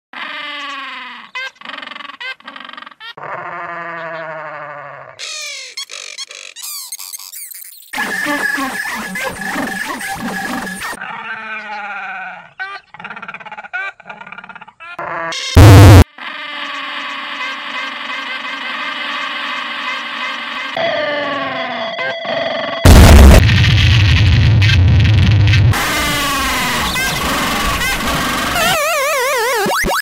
15 _Penguin_ Sound Variations in sound effects free download